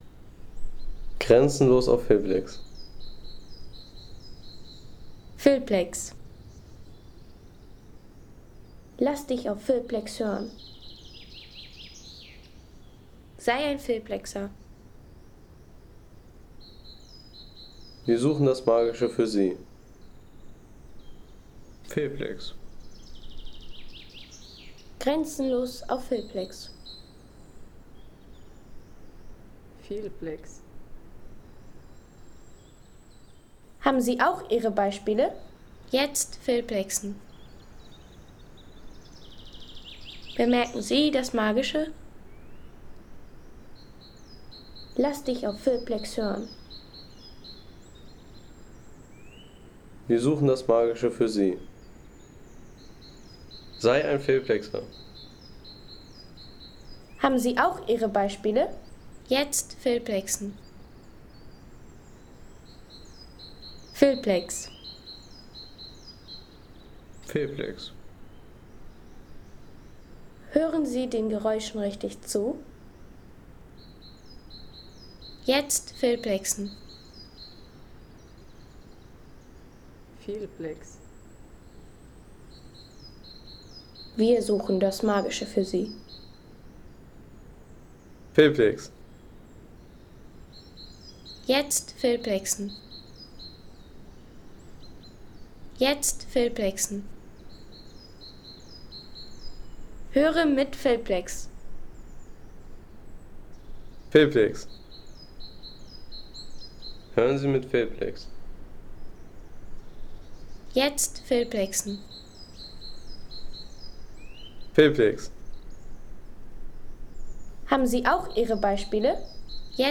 Möllner Wald Home Sounds Landschaft Wälder Möllner Wald Seien Sie der Erste, der dieses Produkt bewertet Artikelnummer: 79 Kategorien: Wälder - Landschaft Möllner Wald Lade Sound.... Pause am Drüsensee im Möllner Wald.